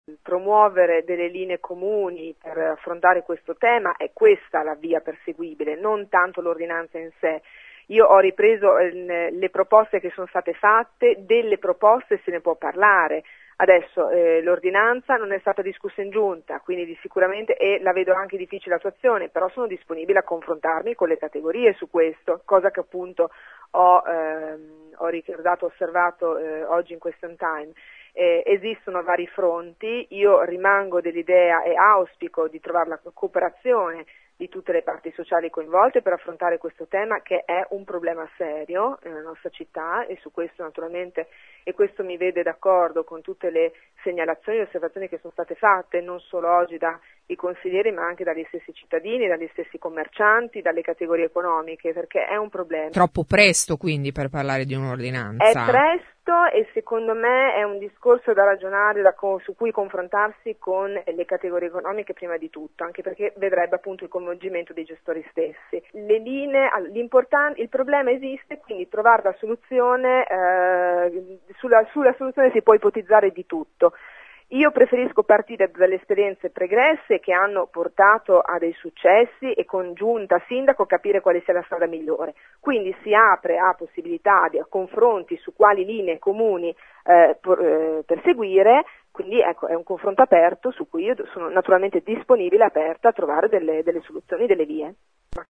Nel pomeriggio abbiamo contattato l’assessore Monti che ha tentato di smorzare, “ma il problema c’è“, ha detto.